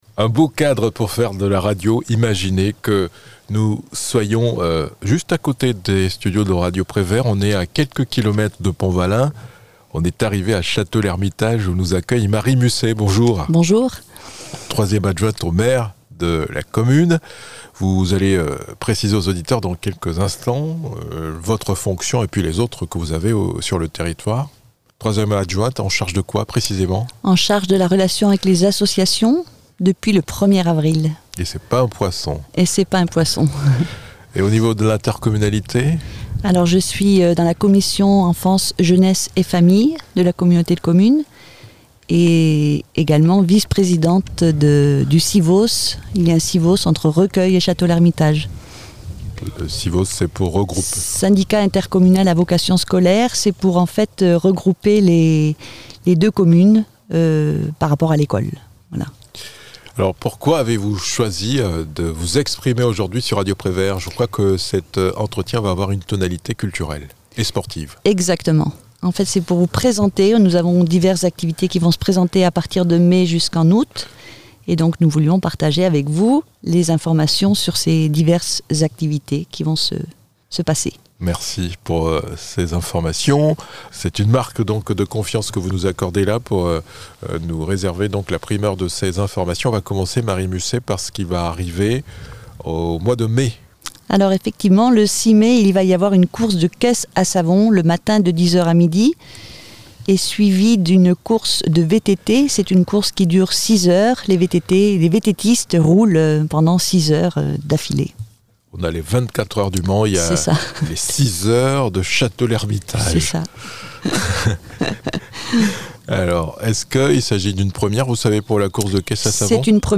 Invitée de Radio Prévert, Marie Musset, 3e adjointe au maire en charge des associations a annoncé les différents événements à l'agenda culturel et sportif. Tout commencera le 6 mai, par une course de caisses à savon de 10h à 13h et les 6h VTT de 15h à 21h.